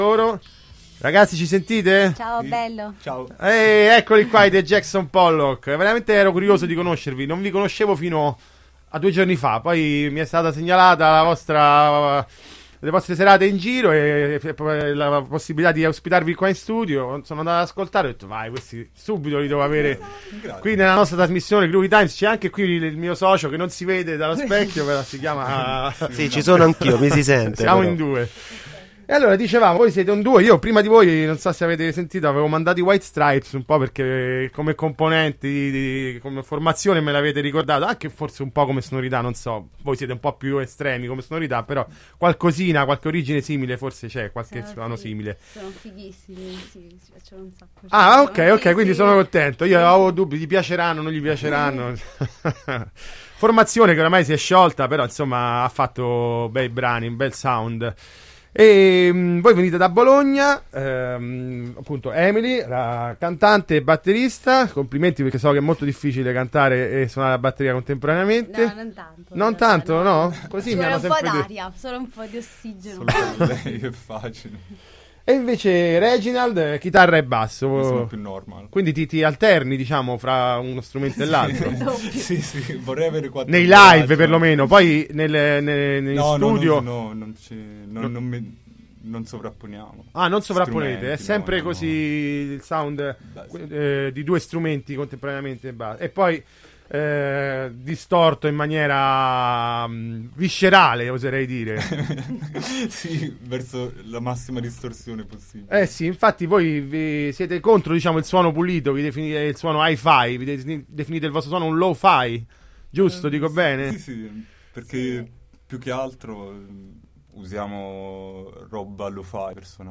Intervista ai The Jackson Pollock del 21/12/2019 | Radio Città Aperta